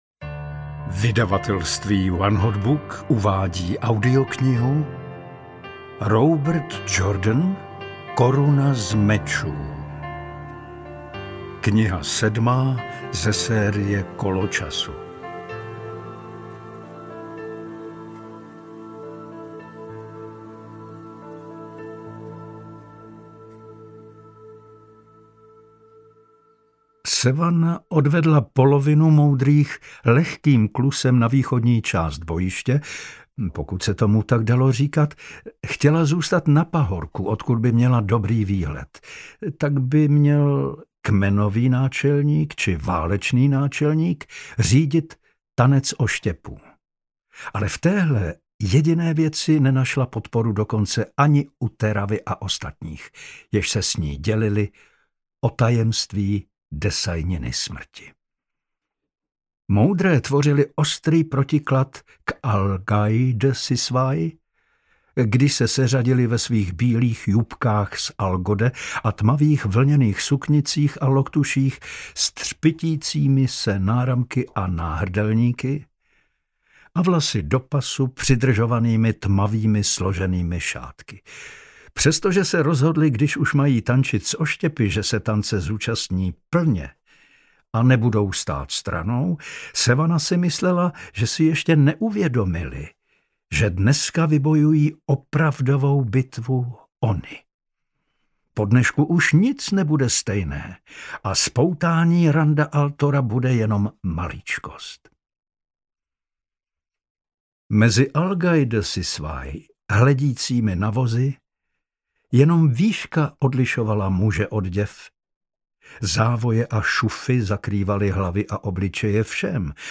Koruna z mečů audiokniha
Ukázka z knihy
• InterpretPavel Soukup